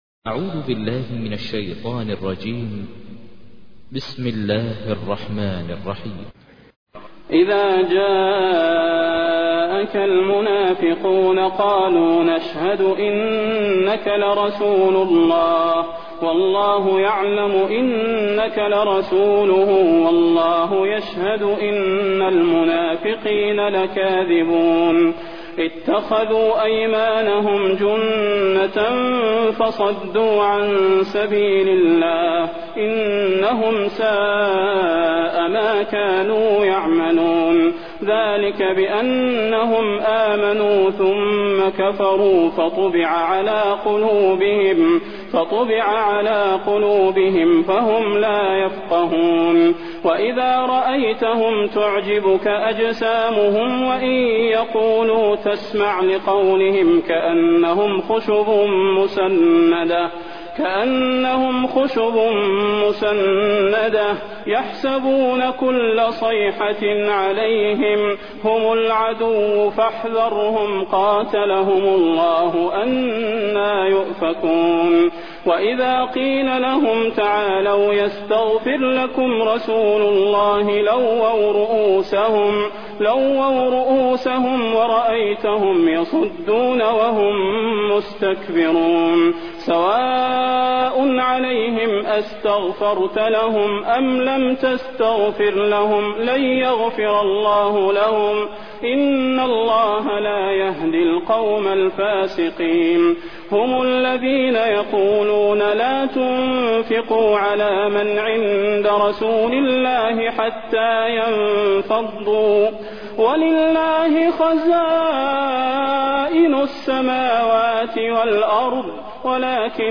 تحميل : 63. سورة المنافقون / القارئ ماهر المعيقلي / القرآن الكريم / موقع يا حسين